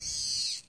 Звук цифровой подзорной трубы: электронный прицел и звуки увеличения